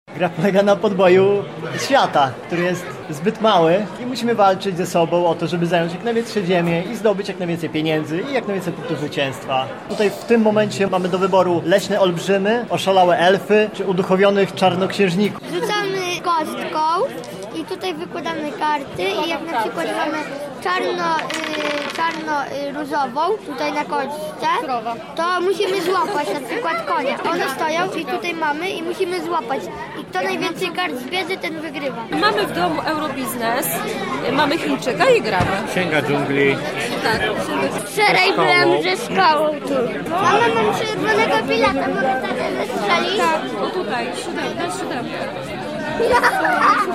O swoich wrażeniach opowiadają uczestnicy.